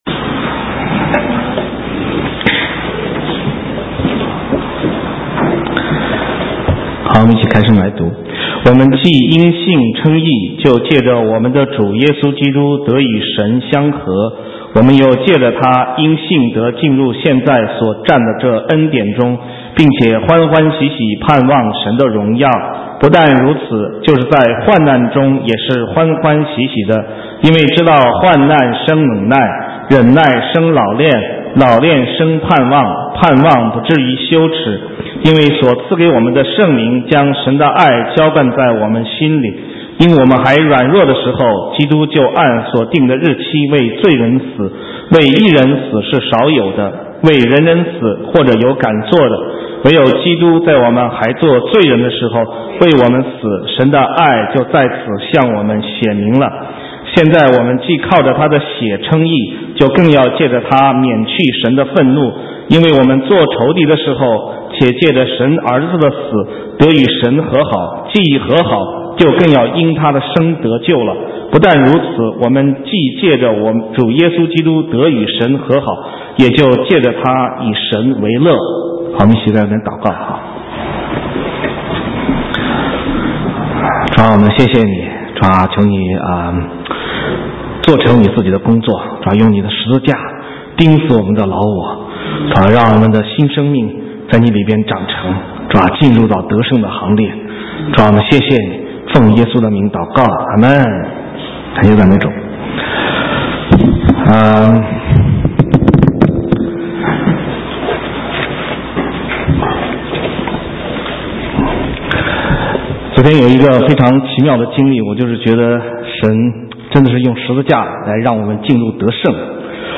神州宣教--讲道录音 浏览：基督徒的喜乐 (2012-03-18)